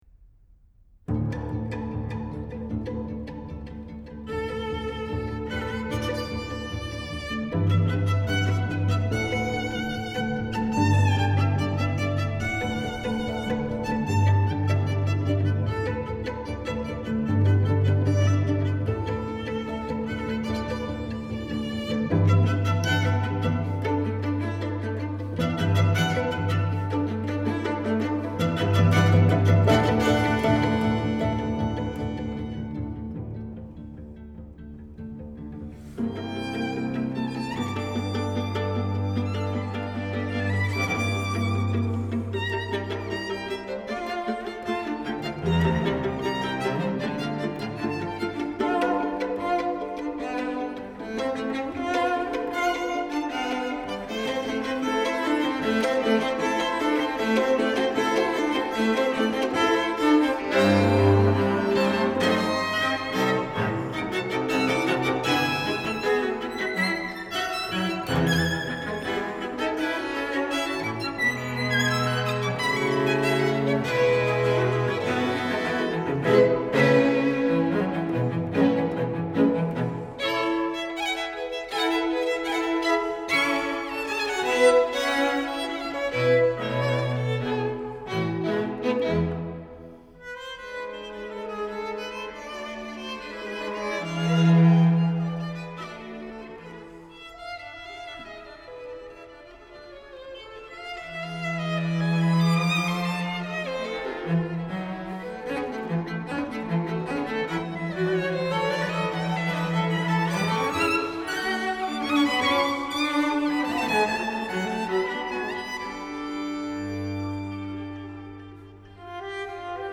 violon
alto
violoncelle